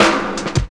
34 SNARE 5.wav